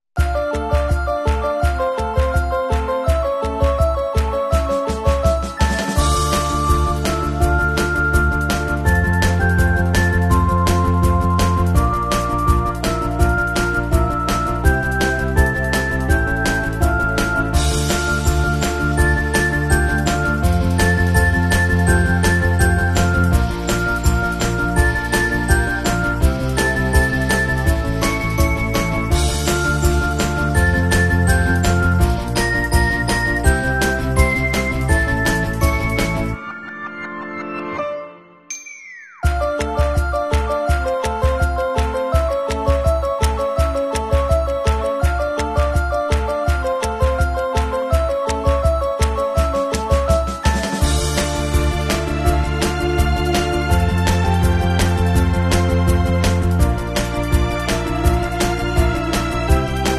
Dubstep Remix